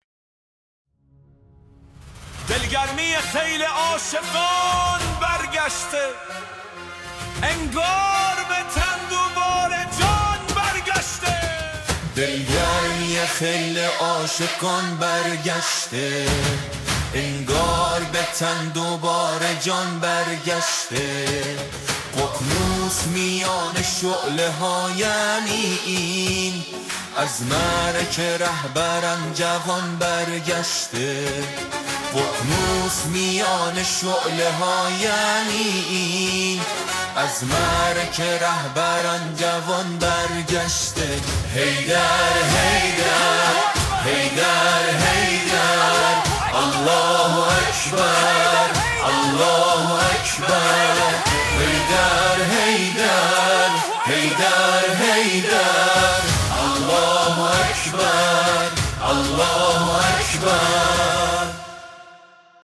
طنین صدای جمعی
ژانر: آهنگ ، سرود انقلابی